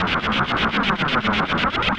Index of /musicradar/rhythmic-inspiration-samples/120bpm
RI_RhythNoise_120-02.wav